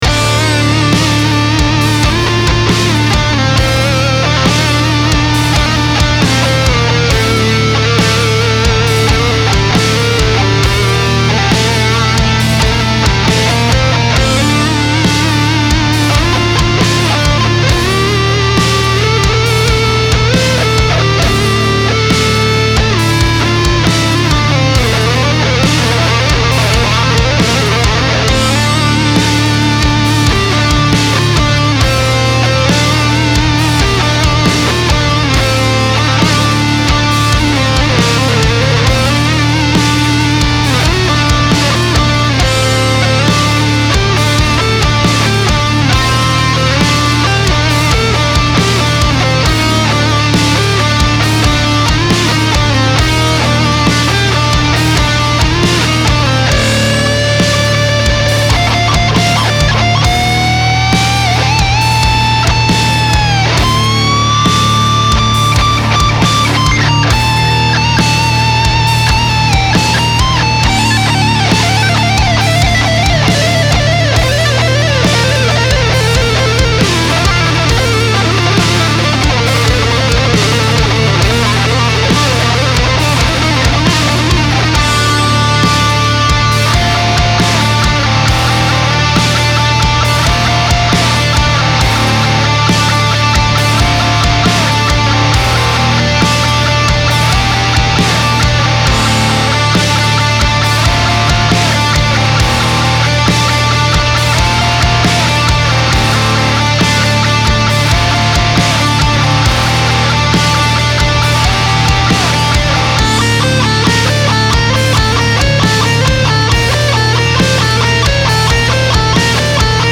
不思議で怪しい雰囲気のメタル